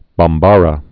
(bäm-bärä)